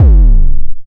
Index of /m8-backup/M8/Samples/breaks/breakcore/earthquake kicks 2
lick the floor kick.wav